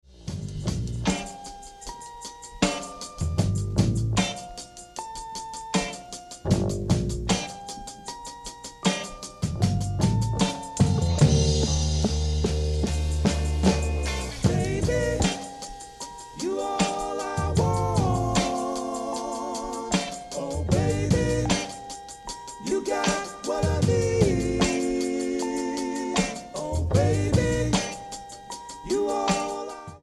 It’s all about the break on side B.
Dub. Roots. Reggae. Calypso. Ska. 2 Tone.